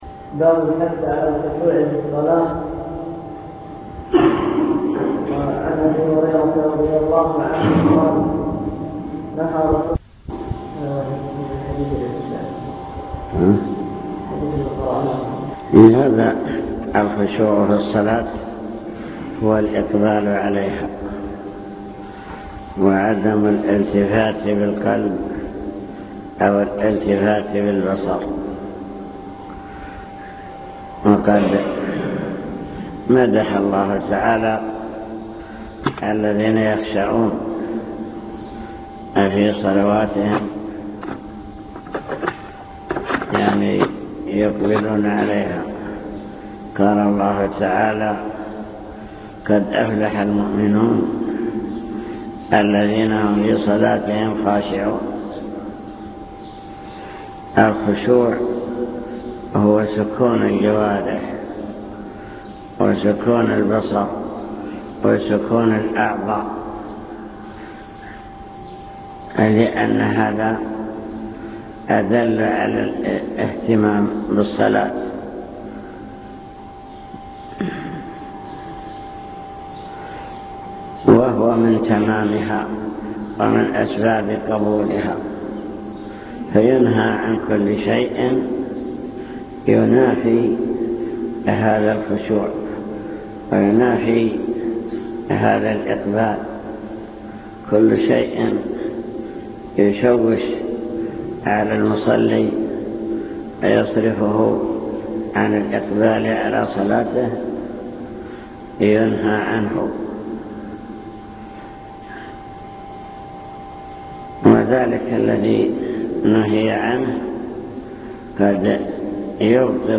المكتبة الصوتية  تسجيلات - محاضرات ودروس  درس في الجمعة مع بلوغ المرام الحث على الخشوع في الصلاة